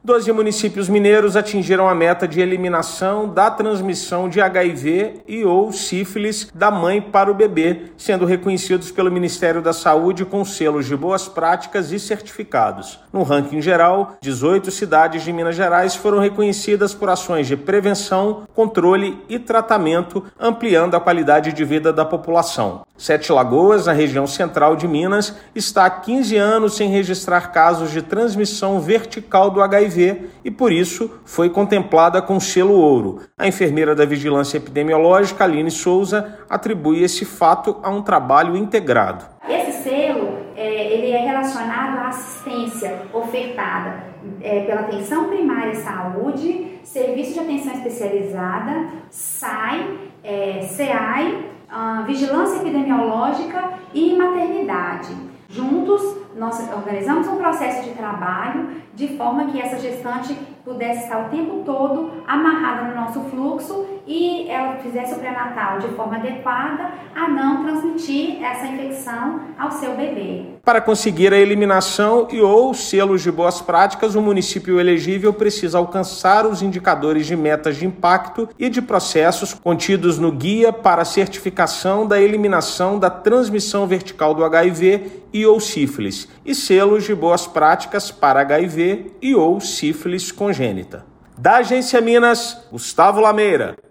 Nas 18 cidades mineiras reconhecidas, ações de prevenção, controle e tratamento têm impedido a transmissão vertical e ampliado a qualidade de vida da população. Ouça matéria de rádio.